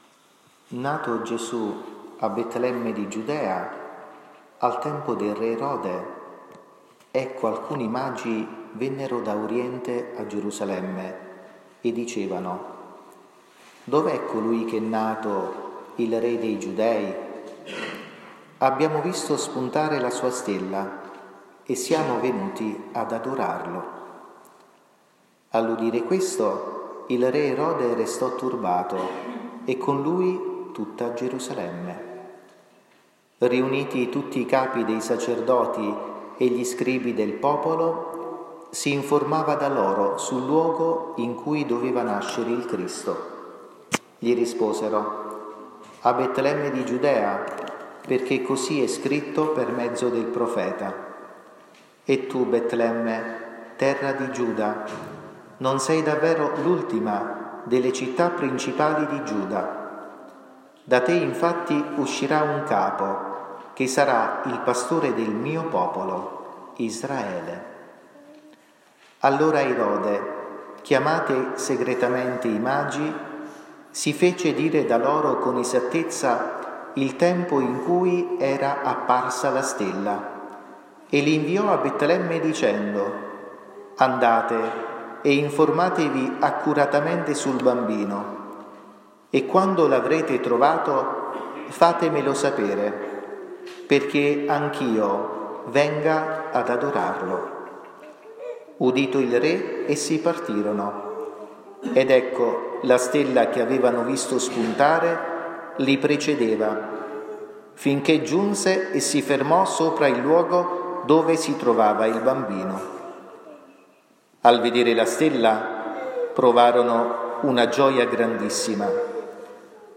omelia-6-gennaio-2023.mp3